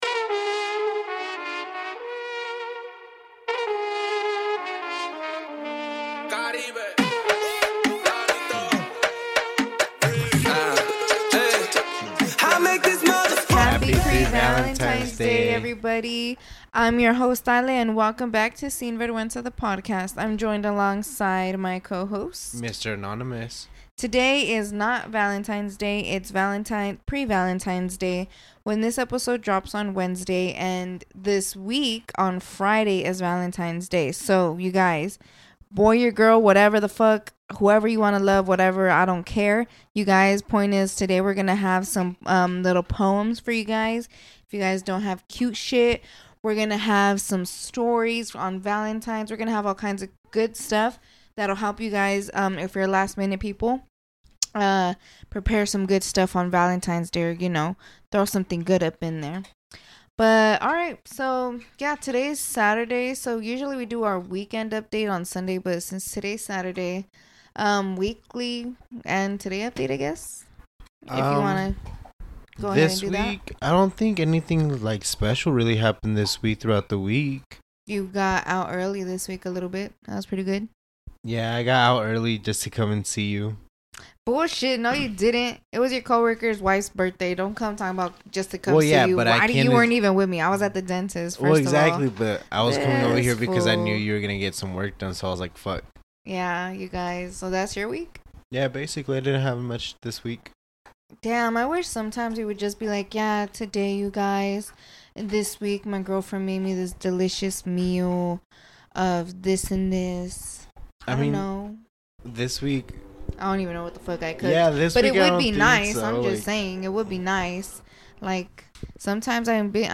For this episode you guys hear your hosts bring out their inner Shakespeare with their own roses are red poems.